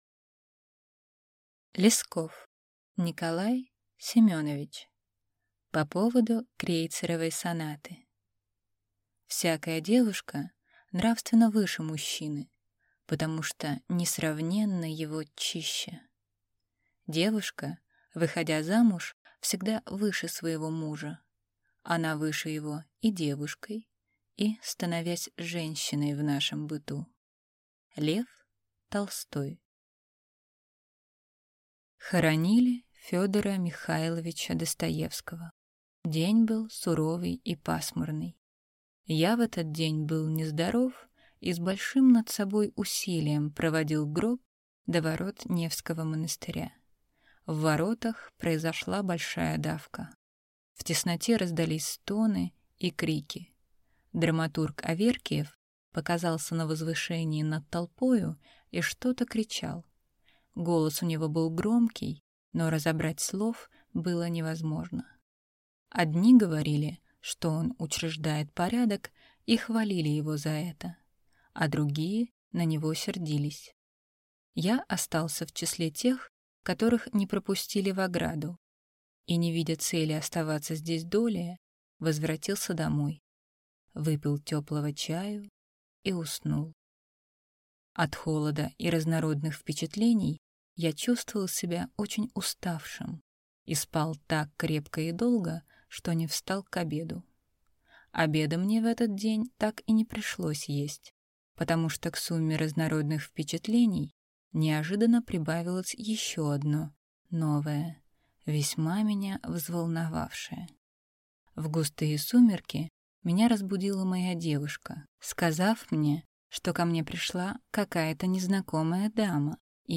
Аудиокнига По поводу «Крейцеровой сонаты» | Библиотека аудиокниг